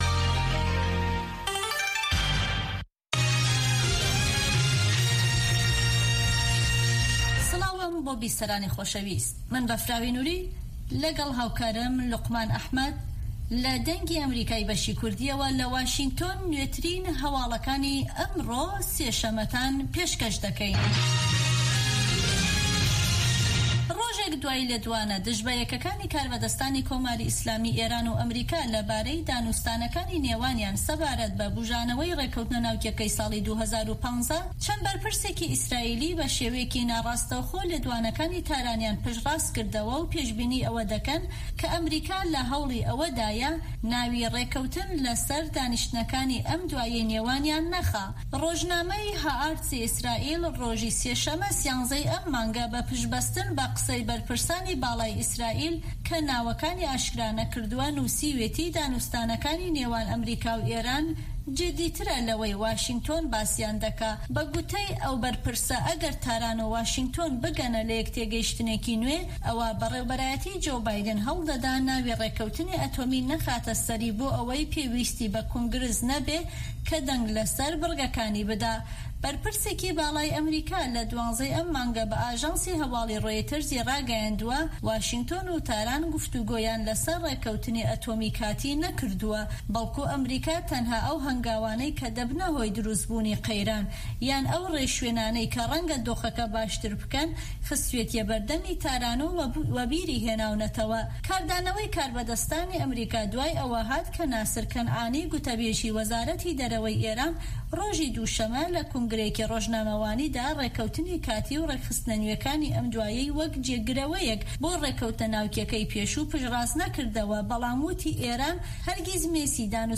Nûçeyên Cîhanê 1